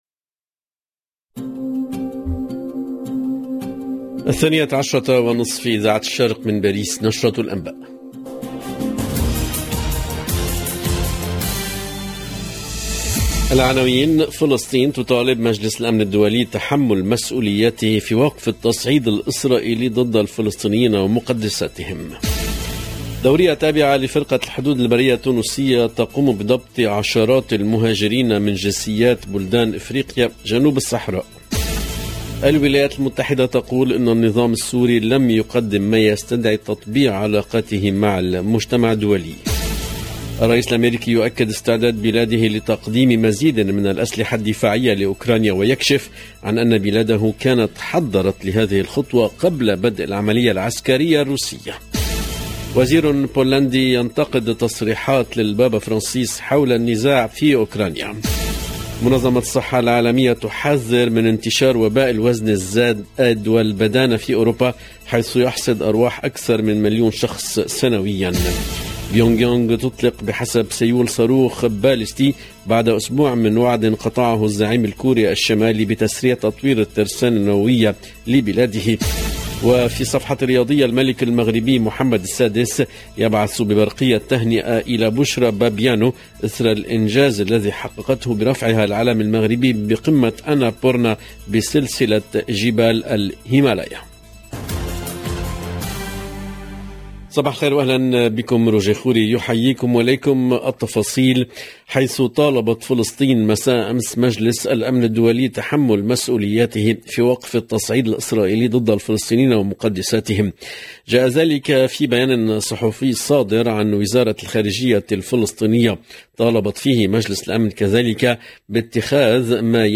EDITION DU JOURNAL DE 12H30 EN LANGUE ARABE DU 4/5/2022